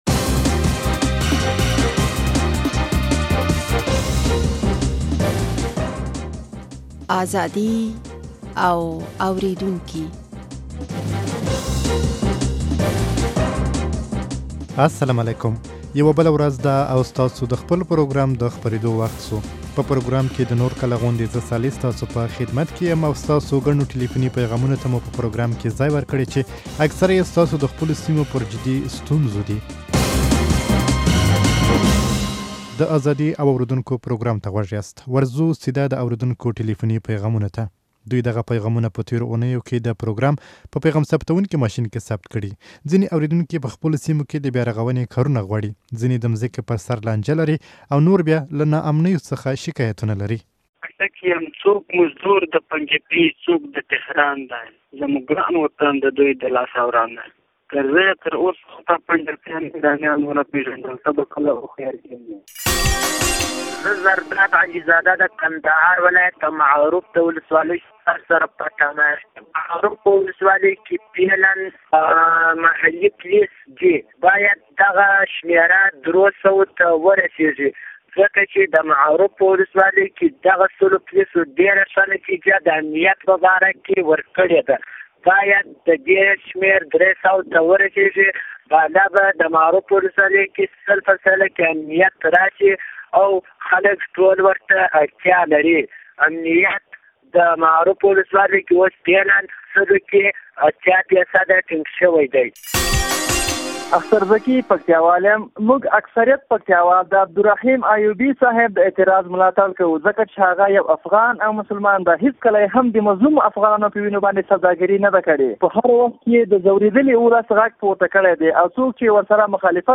ستاسو ګڼو ټليفوني پيغامونو ته مو په پروګرام کې ځاى ورکړى، چې اکثره يې ستاسو د خپلو سيمو پر جدي ستونزو دي ازادي او اورېدونکي - د وري ١٨ مه تاسو په دې شمېرو د دې پروګرام لپاره په اتومات پيغام ثبتوونکي ماشين کې خپل ټلیفوني پیغامونه ثبتولی شئ.